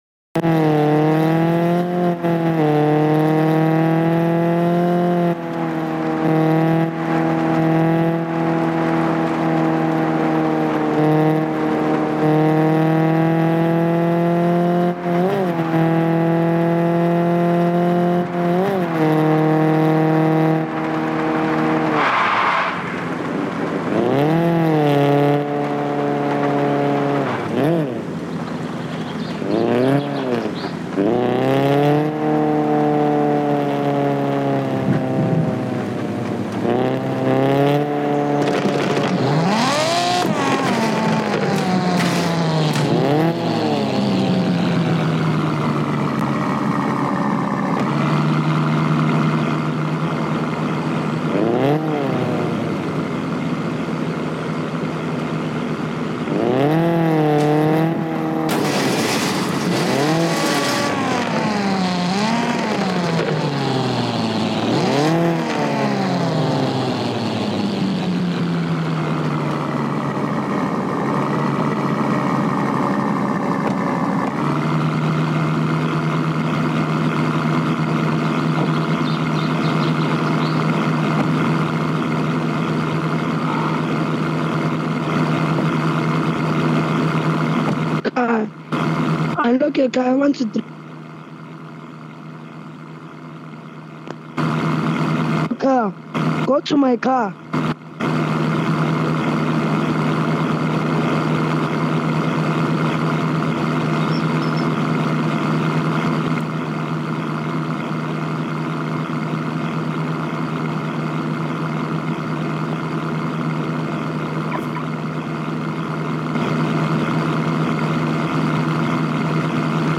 Car Parking Multiplayer | Car sound effects free download
Car Delivery Mission Gameplay